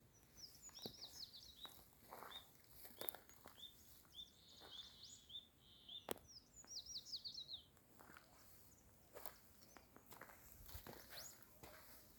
Andean Tinamou (Nothoprocta pentlandii)
Province / Department: Catamarca
Location or protected area: Santa María
Condition: Wild
Certainty: Recorded vocal